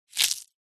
Звуки насекомых
Звук раздавленного жука под ногой хруст